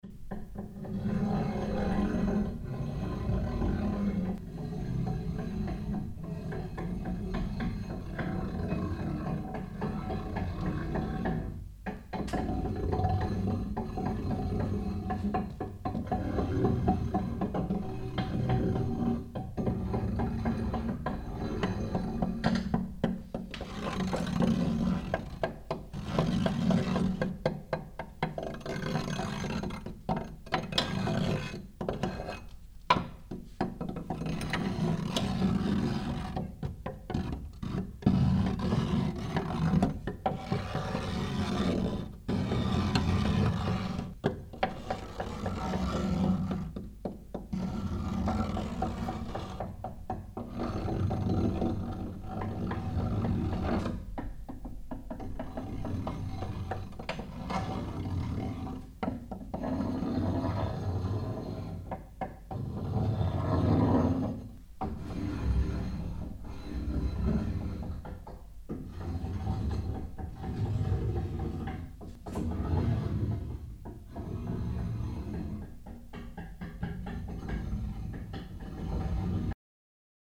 Уривок аудіоскульптури Кришталь.mp3